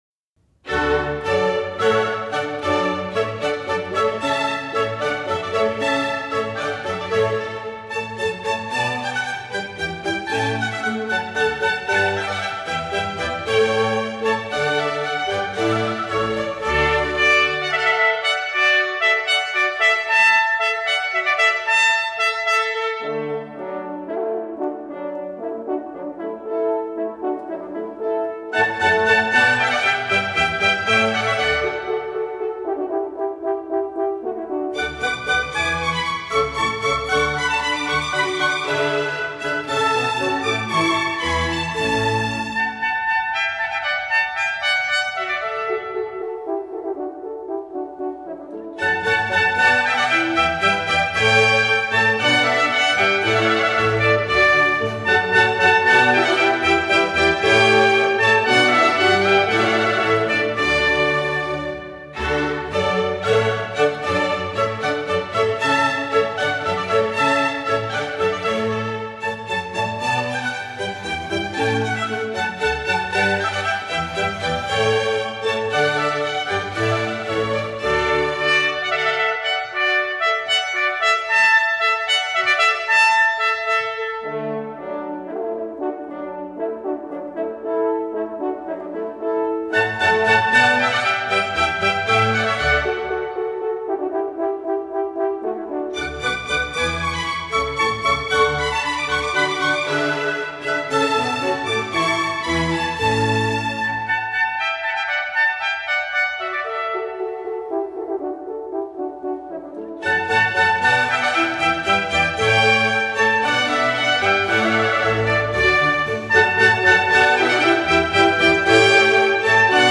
号笛舞曲